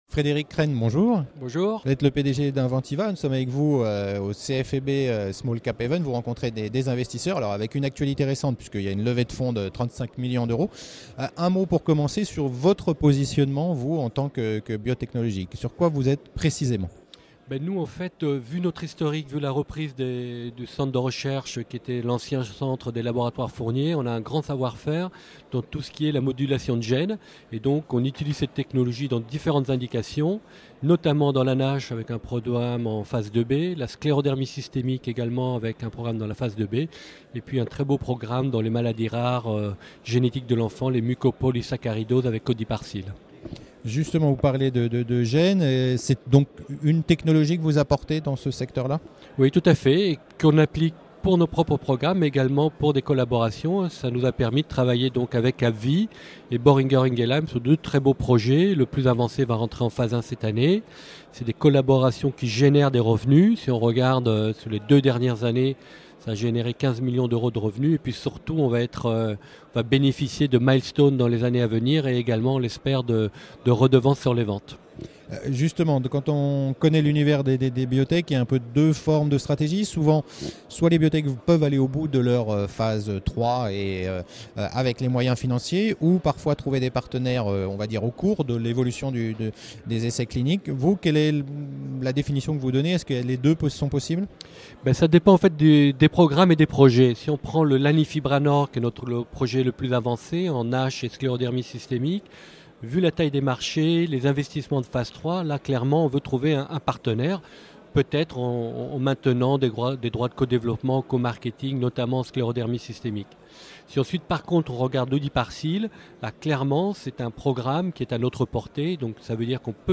A cette occasion, plusieurs dirigeants sont venus parler de leur stratégie et de leurs dernières actualités.